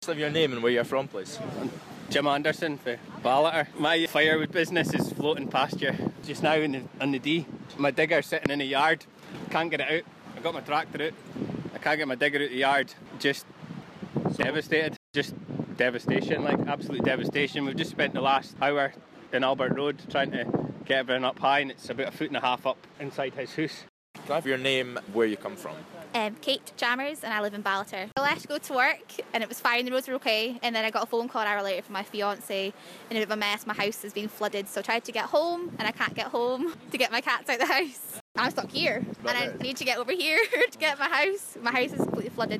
LISTEN: Northsound News speaks to those affected by Ballater floods